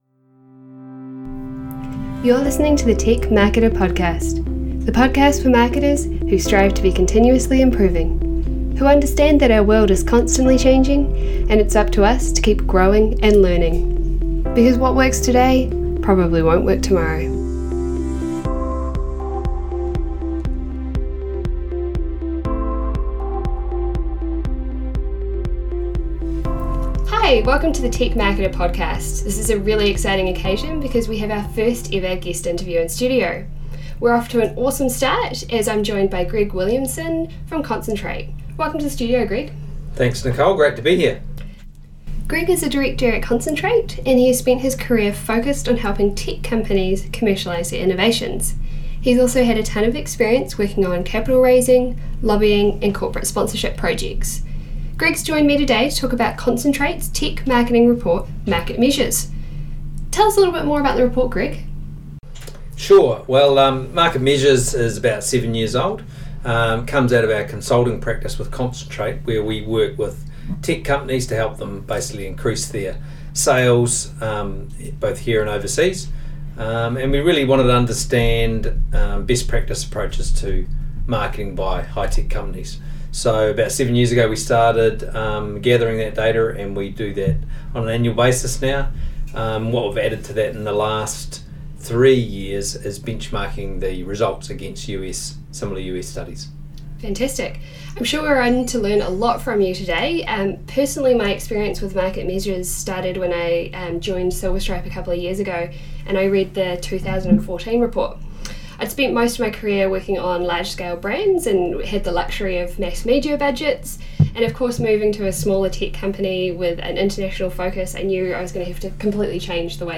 If you tuned into Episode 0: The Three Whys, you’ll know to expect a mix of short knowledge bites with tech interviews.